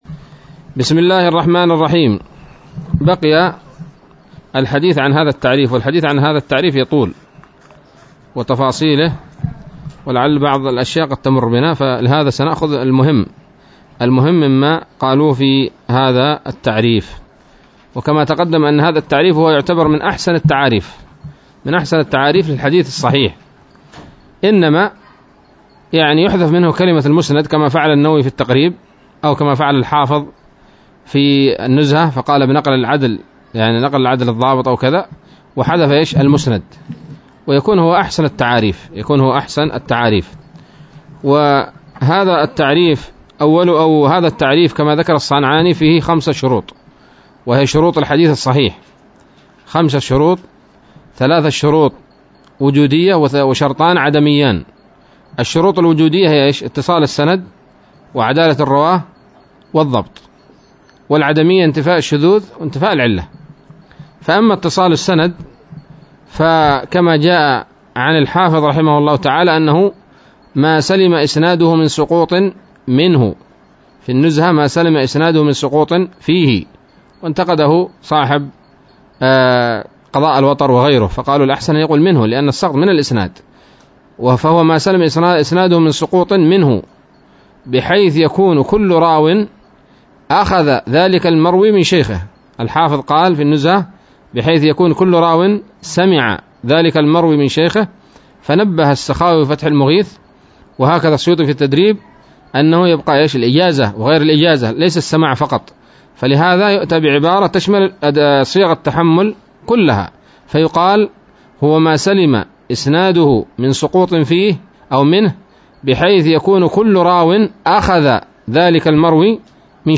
الدرس الخامس من مقدمة ابن الصلاح رحمه الله تعالى